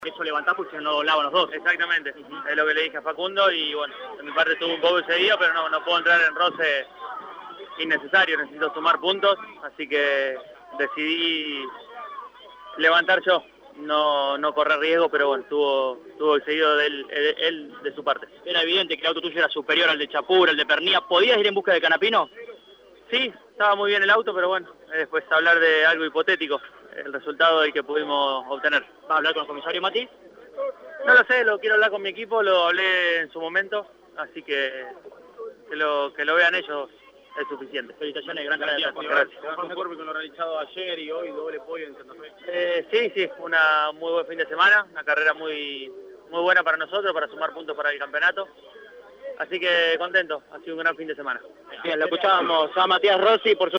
• La palabra de Matías Rossi por Radio Eme luego de su tercer puesto en el callejero.